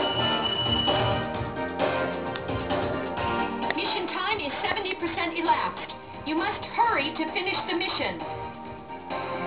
The option screen also allows players to select either a female English speaking (American accented) voice or a female Japanese voice for the operator.